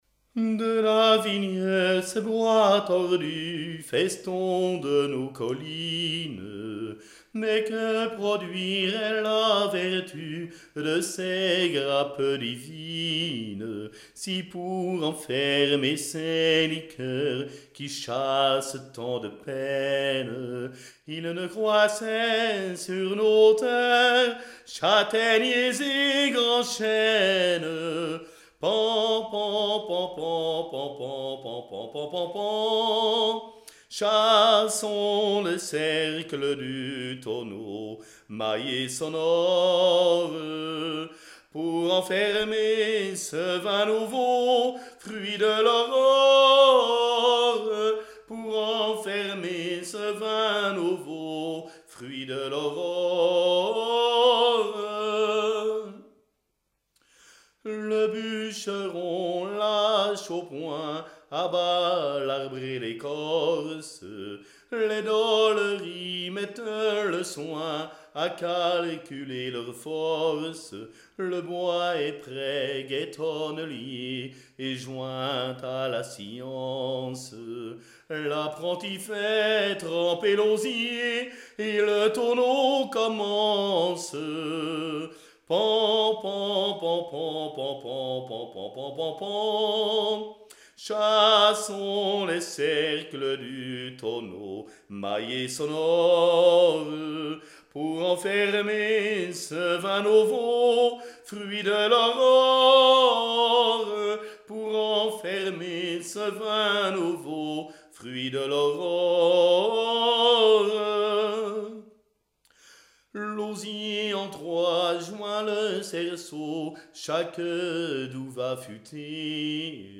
Genre strophique
Chansons traditionnelles et populaires